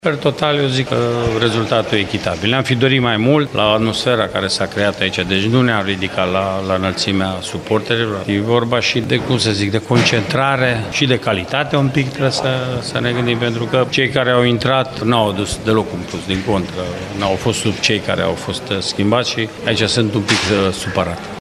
Antrenorul UTA-ei, Mircea Rednic, consideră că echipa sa nu s-a ridicat la înălțimea momentului: